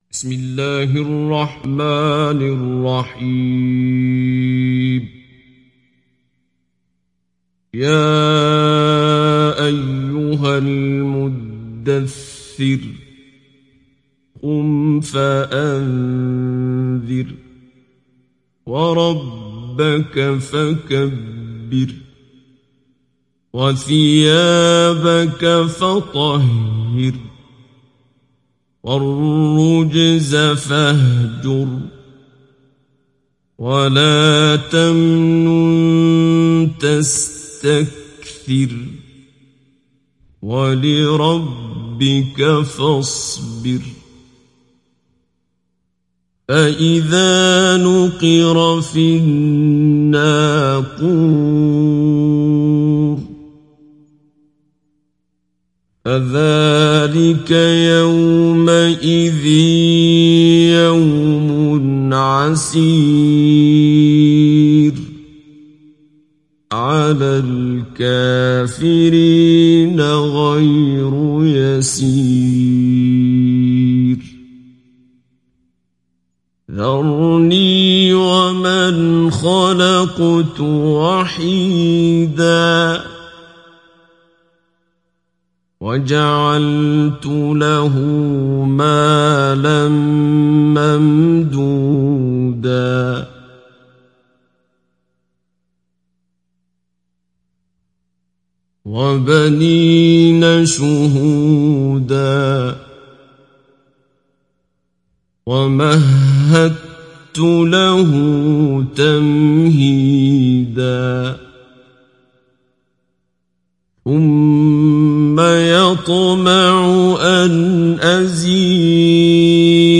تحميل سورة المدثر mp3 بصوت عبد الباسط عبد الصمد مجود برواية حفص عن عاصم, تحميل استماع القرآن الكريم على الجوال mp3 كاملا بروابط مباشرة وسريعة
تحميل سورة المدثر عبد الباسط عبد الصمد مجود